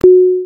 5-5.クリック音？（ポン！）
軽く短く「ポン！」という音です。
飛行機が降下する時、機内アナウンスで鳴る音のイメージです。
pong.mp3